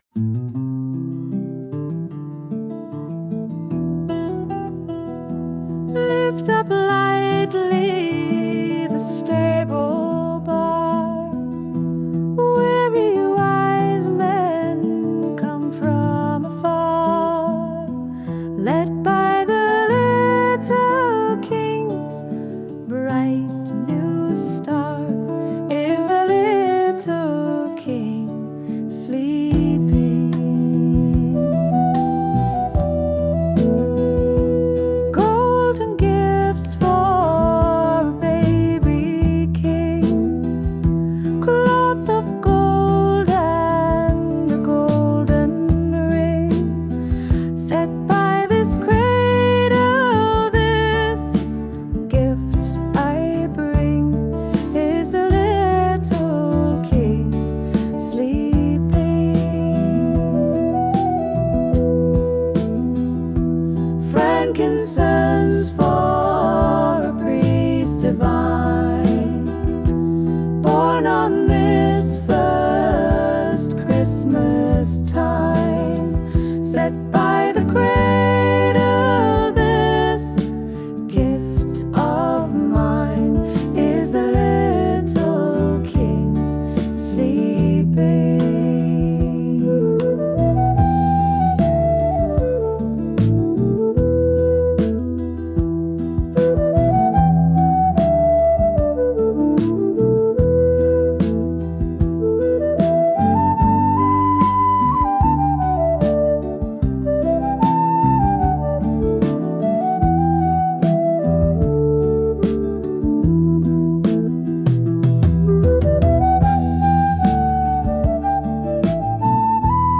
This one I just created a gentle folk tune with female vocalist.